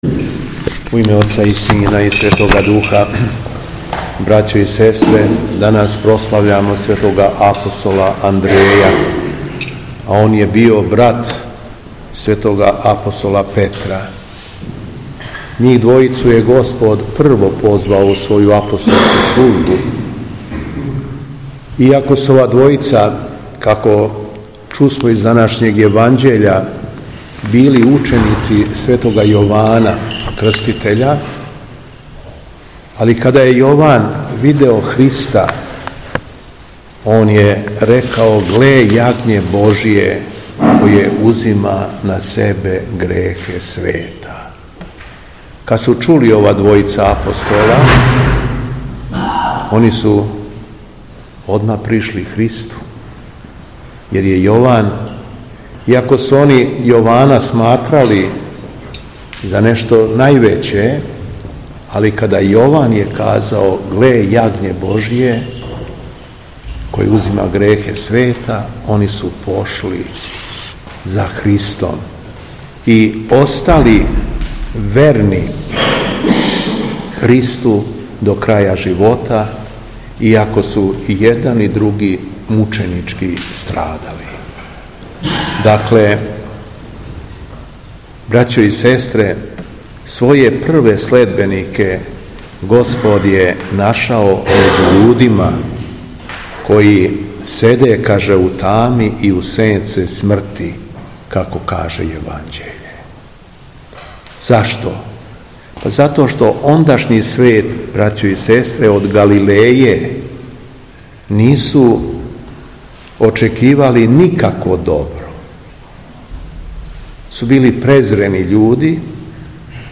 Беседа Његовог Преосвештенства Епископа шумадијског г. Јована
После прочитаног Јеванђелског зачала, Епископ се обратио сабраном народу рекавши: